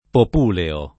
[ pop 2 leo ]